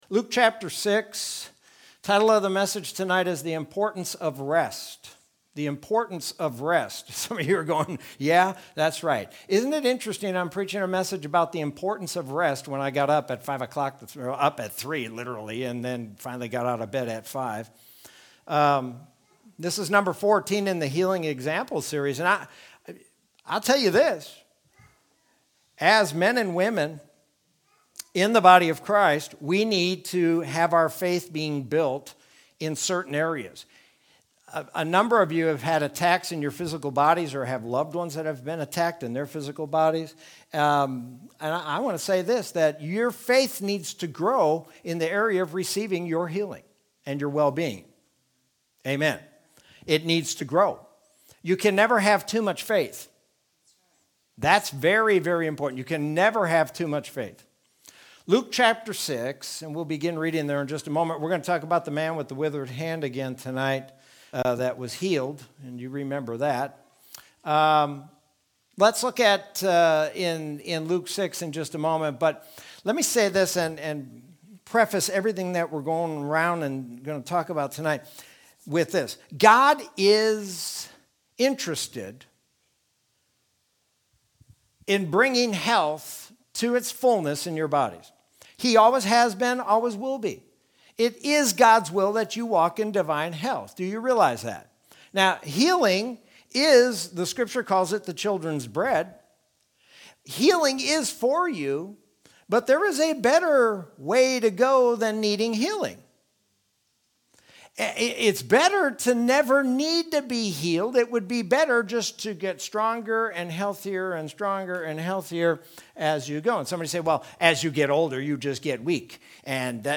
Sermon from Wednesday, May 5th, 2021.